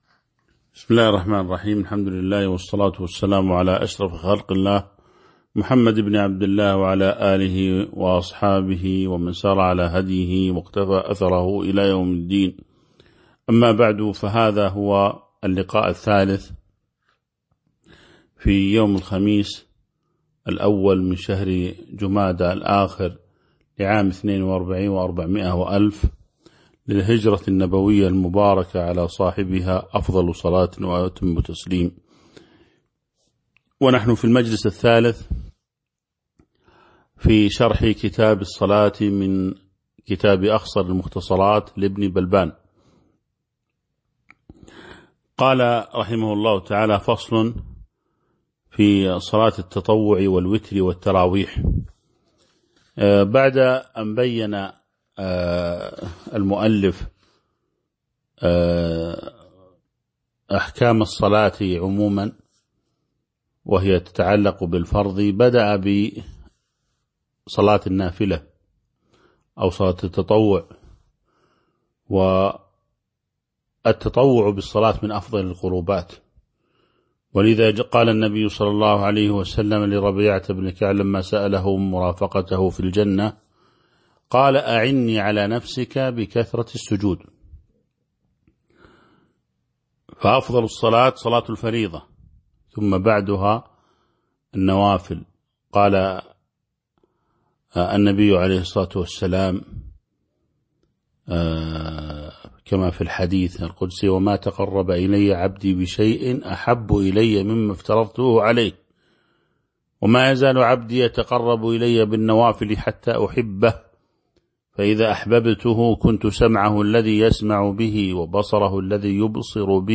تاريخ النشر ١ جمادى الآخرة ١٤٤٢ هـ المكان: المسجد النبوي الشيخ